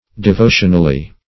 \De*vo"tion*al*ly\